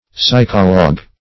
Psychologue \Psy"cho*logue\, n.